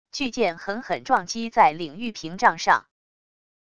巨剑狠狠撞击在领域屏障上wav音频